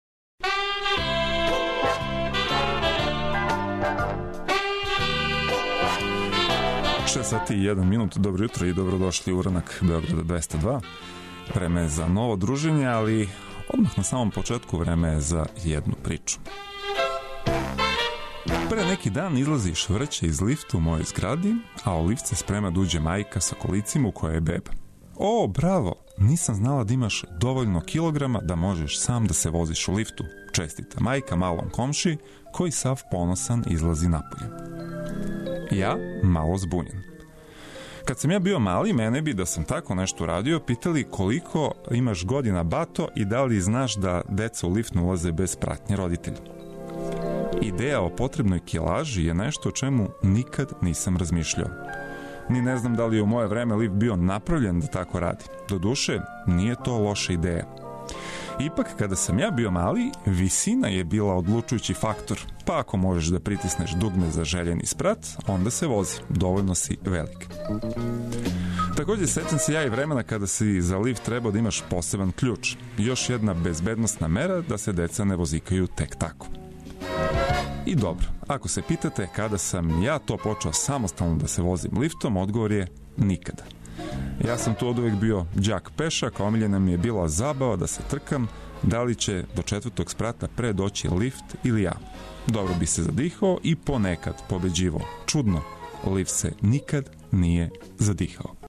Уз добру музику, ведре теме постају још ведрије, а лешкарење непроцењиво задовољство.